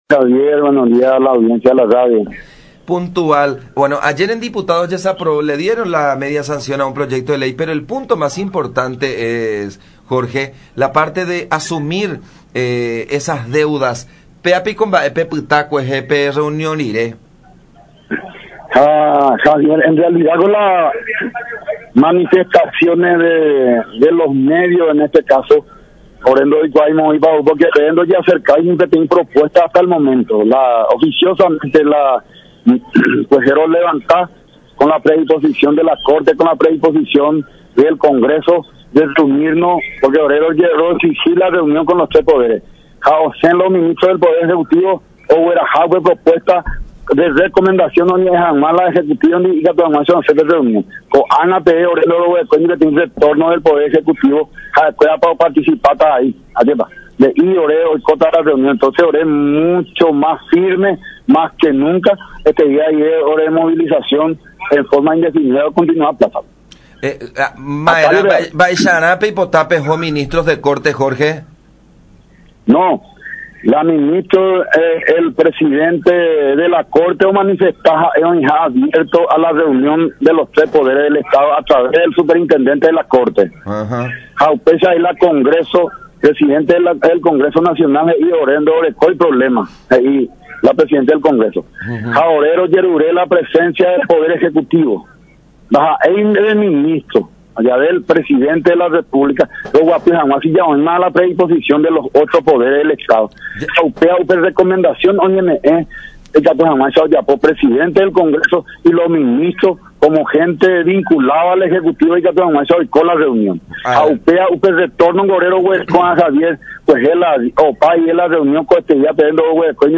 conversó con radio La Unión y sostuvo que hasta el momento no han recibido ni un tipo de propuesta por parte del Poder Ejecutivo “No se nos acercó ni una propuesta hasta el momento.